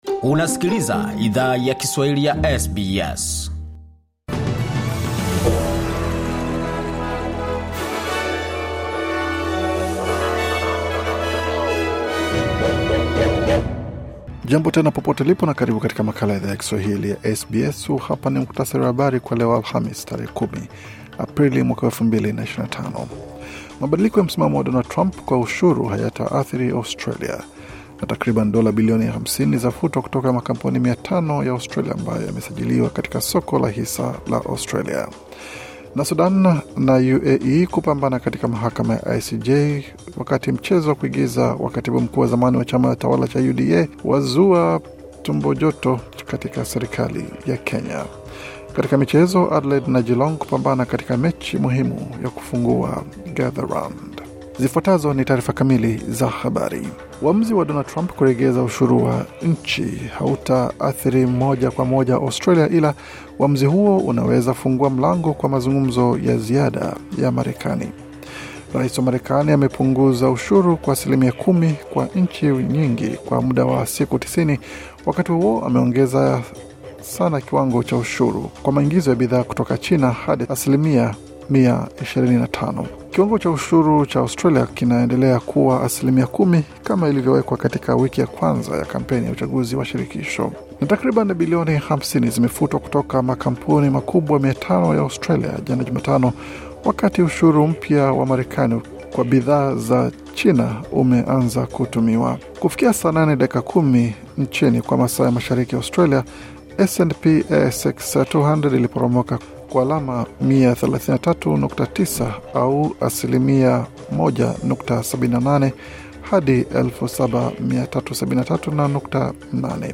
Taarifa ya Habari 10 Aprili 2025